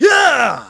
Riheet-Vox_Attack7.wav